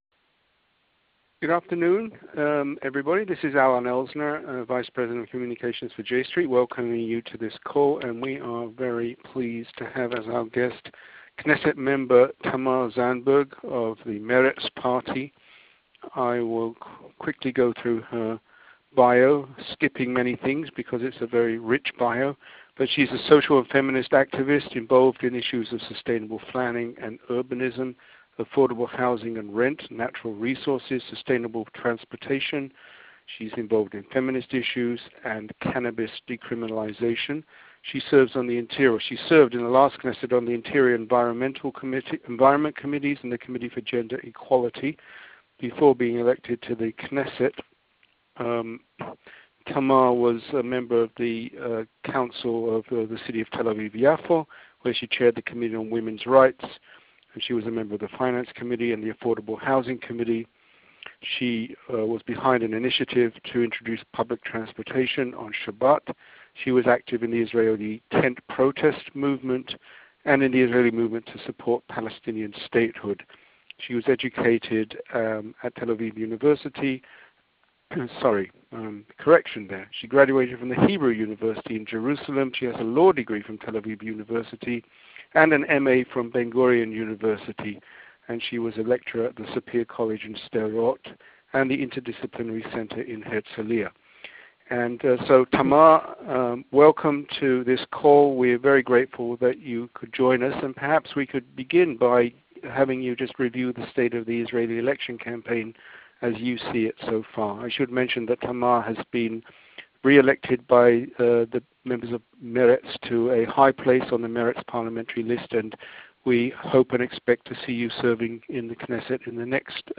Listen to our briefing call with MK Tamar Zandberg